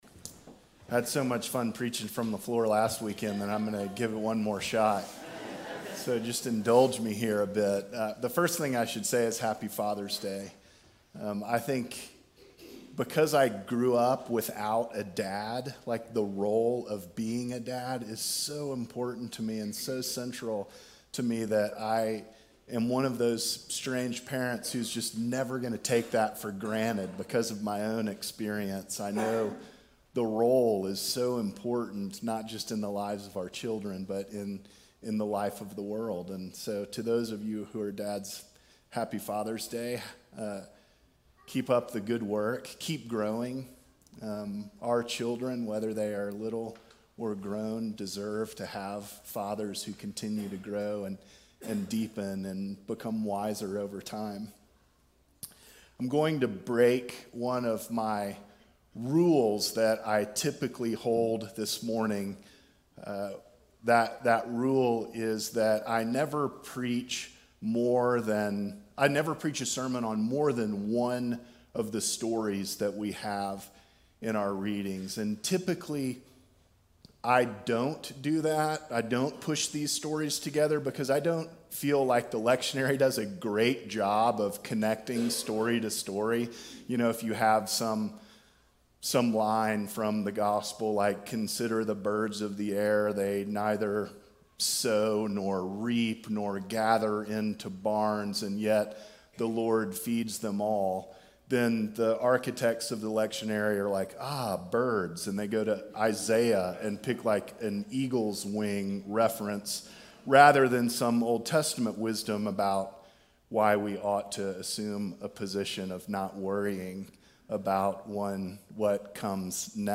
Fourth Sunday after Pentecost
Sermons from St. John's Episcopal Church